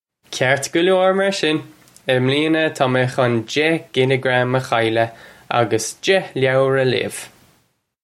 Kart galore, mar shin. Im lee-inuh, taw may khun dekh killagram uh khyle-uh uggus dekh low-er uh lay-uv!
This is an approximate phonetic pronunciation of the phrase.